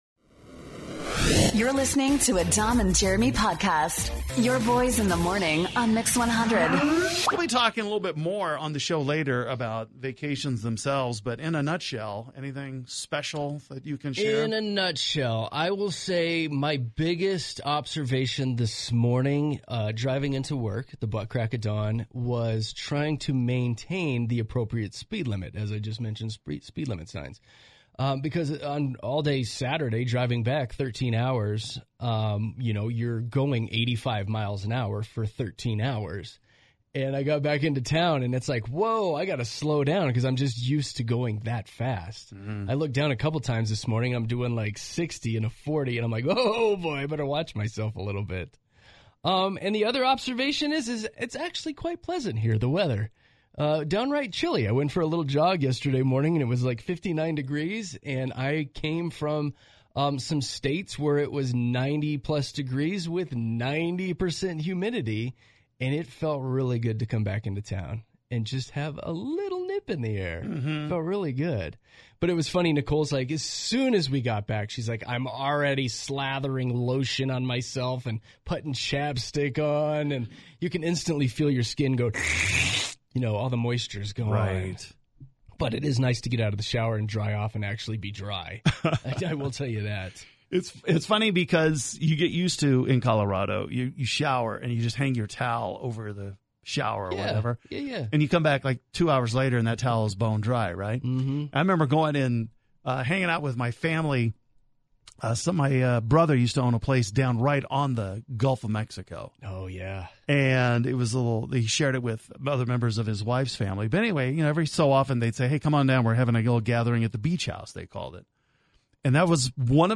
After a nice little vacay, your Boyz in the Morning are back live on air! Hear about our vacation here!